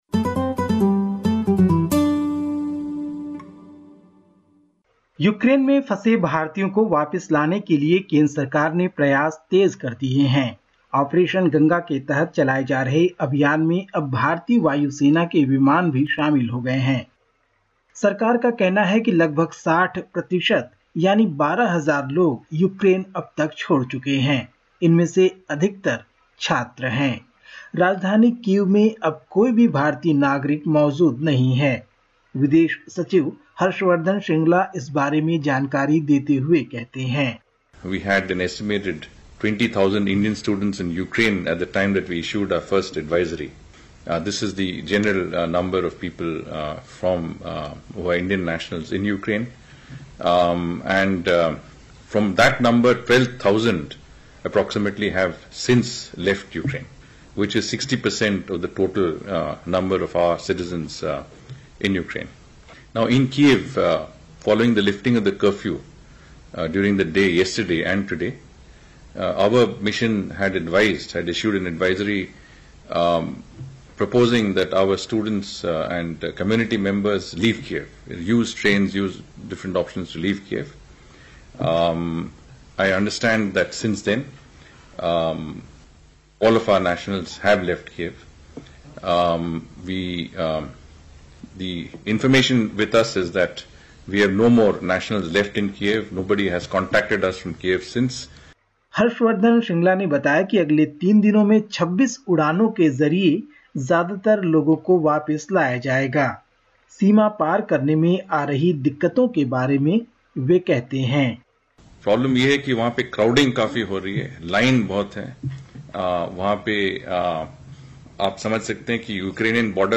Listen to the latest SBS Hindi report from India. 02/03/22